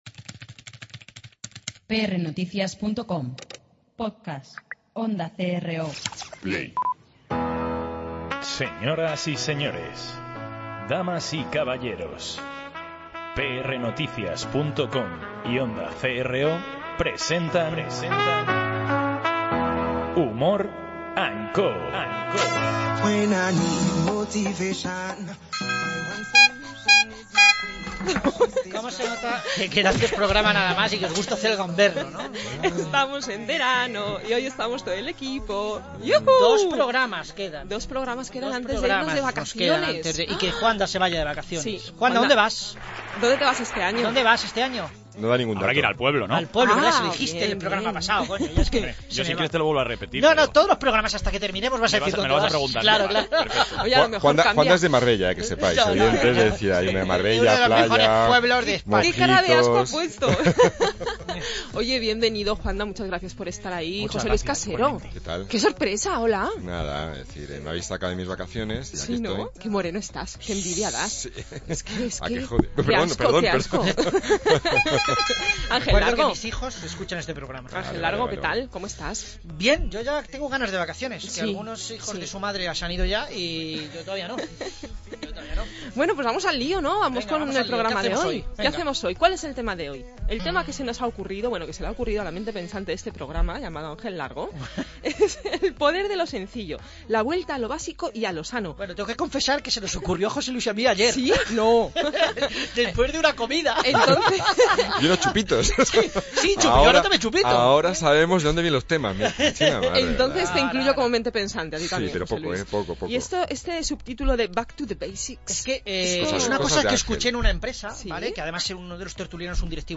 Hoy es un día especial, tan solo quedan dos días para irnos de vacaciones, por lo cual vamos a despedir la entrevista cantando todos juntos la canción de Lobo hombre en París.